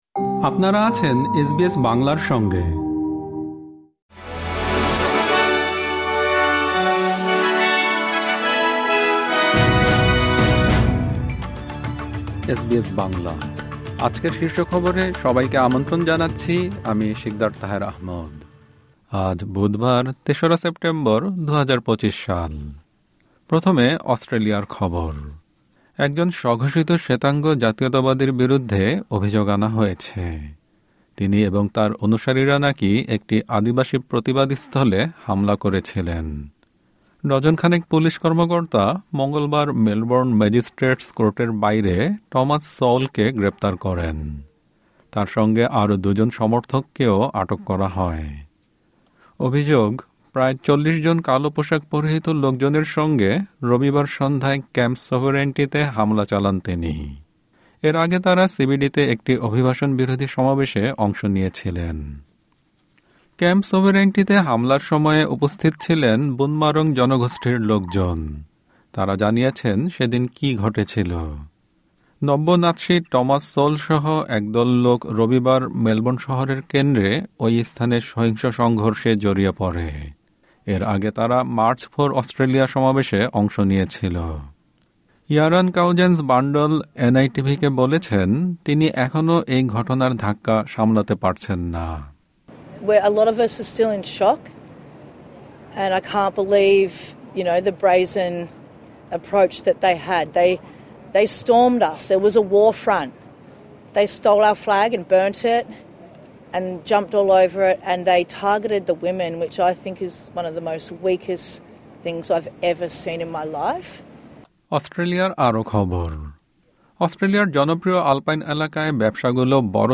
এসবিএস বাংলা শীর্ষ খবর: ৩ সেপ্টেম্বর, ২০২৫